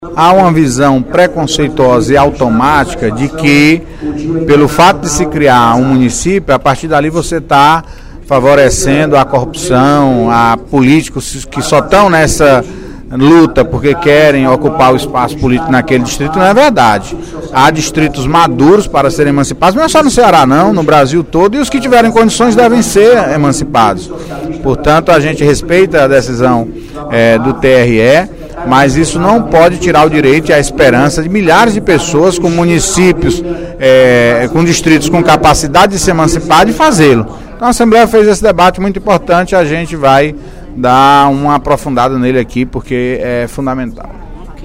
O líder do Governo na Assembleia Legislativa, deputado Antonio Carlos (PT), disse, na sessão plenária desta quinta-feira (22/03), que a criação de novos municípios não deve ser vista como a fomentação da corrupção no País.